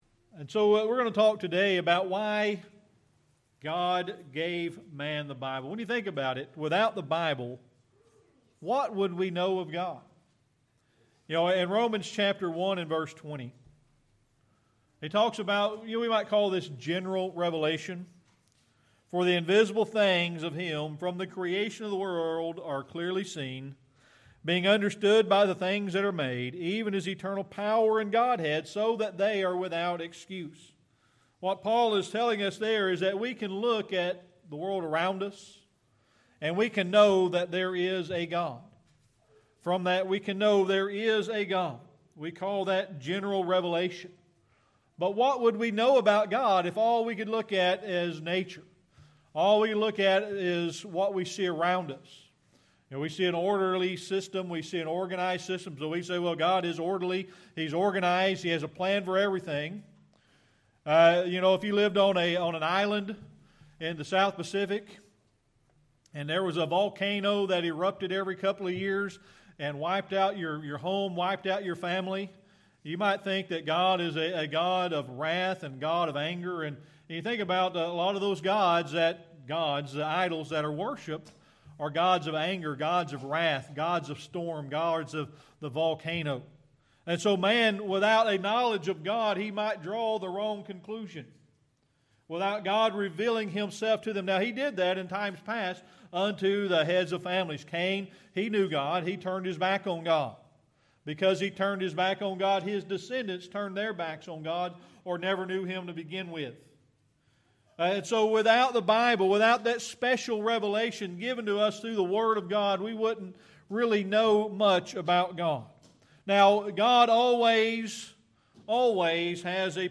Series: Sermon Archives
Isaiah 55:8-11 Service Type: Sunday Morning Worship When you think about it